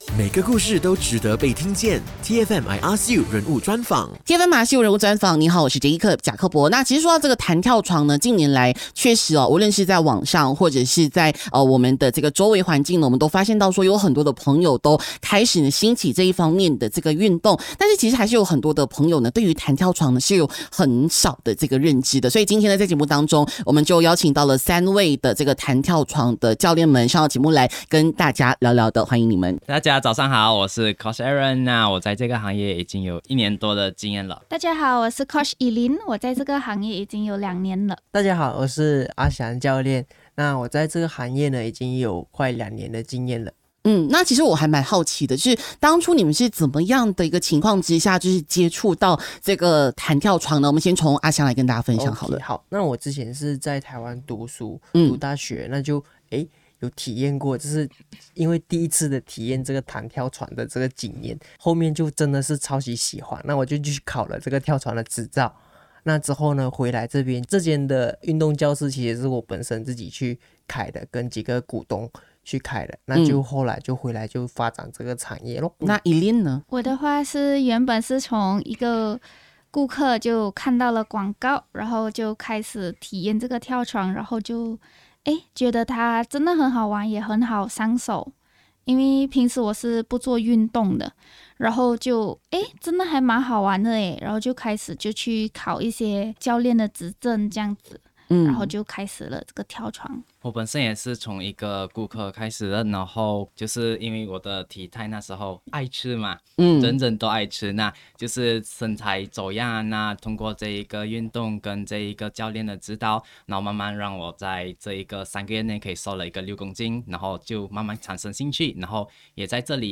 1113 TEA FM I ASK U 人物专访 弹跳床教练.mp3